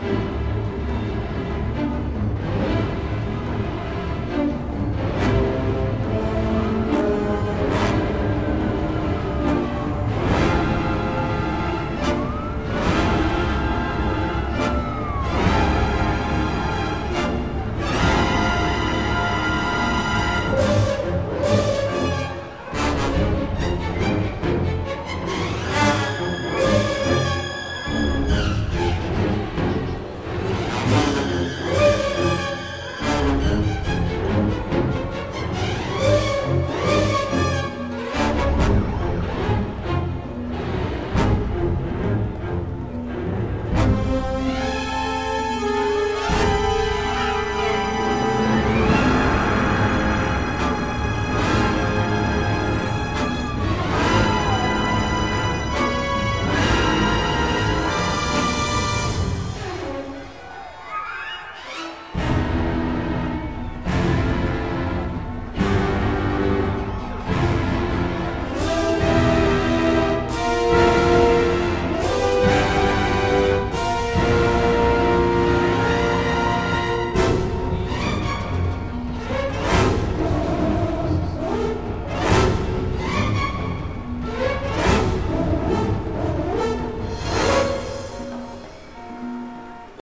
Low quality samples from the game XA music files: